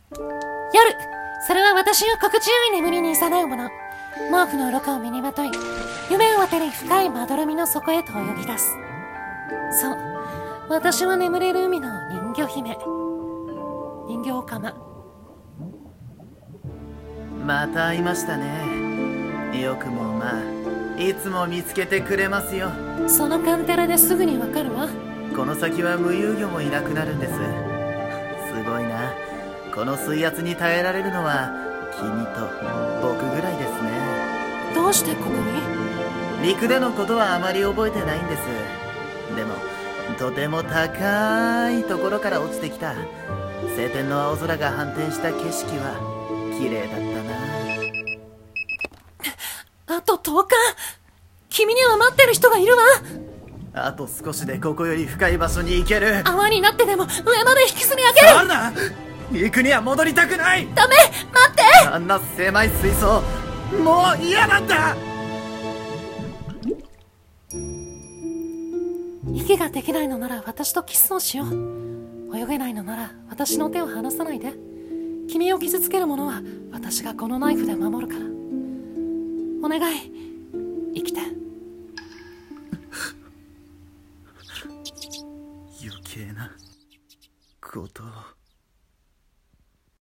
CM風声劇「睡深8400mより